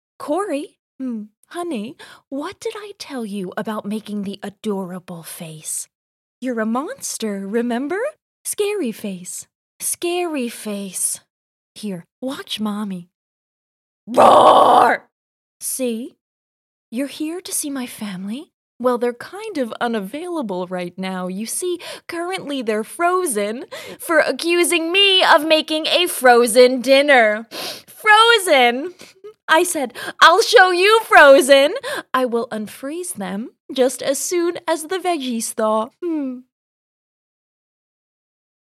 hell, fein, zart, sehr variabel
Game